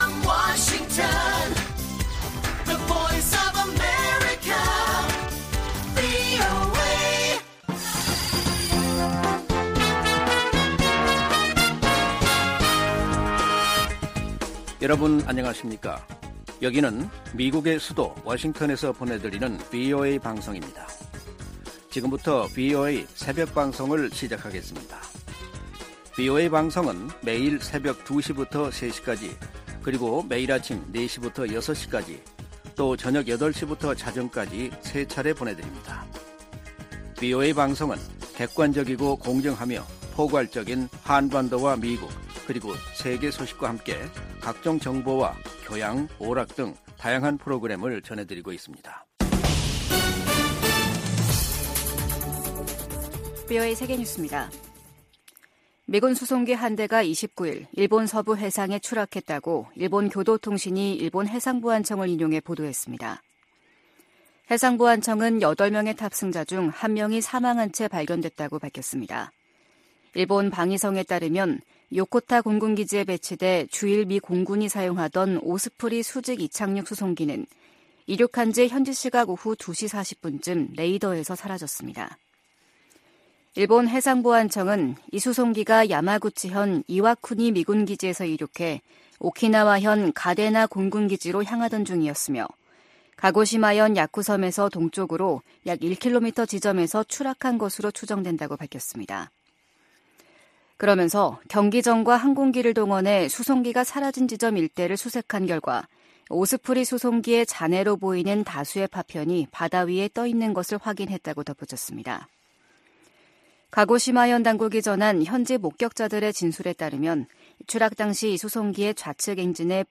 VOA 한국어 '출발 뉴스 쇼', 2023년 11월 30일 방송입니다. 미국 정부는 북한의 정찰위성 발사를 규탄하면서 면밀히 평가하고, 러시아와의 협력 진전 상황도 주시하고 있다고 밝혔습니다. 미 국무부는 북한이 비무장지대(DMZ) 내 최전방 감시초소(GP)에 병력과 장비를 다시 투입한 데 대해 긴장을 부추기고 있다고 비판했습니다. 북한 정찰위성은 고화질 사진을 찍을 수 없다고 전문가들이 평가했습니다.